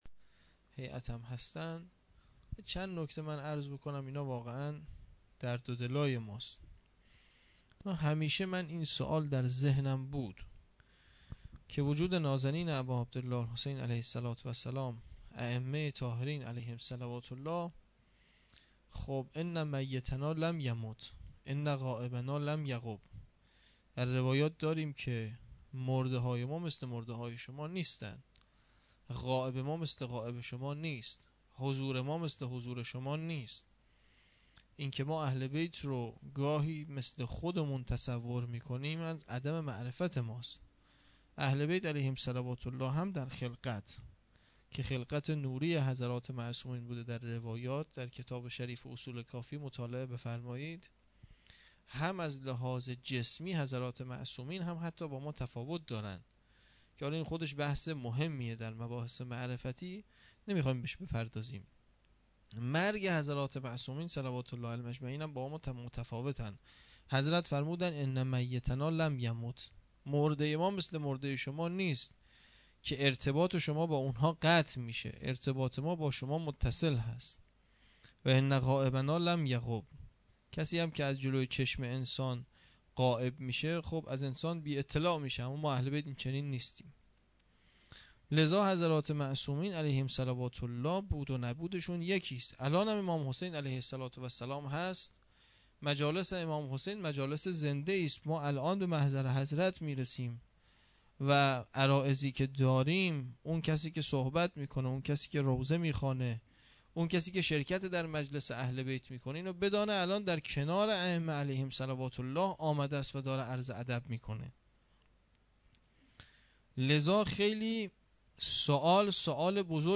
pishvaz moharam 93 sokhanrani (2).mp3
pishvaz-moharam-93-sokhanrani-2.mp3